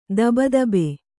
♪ daba dabe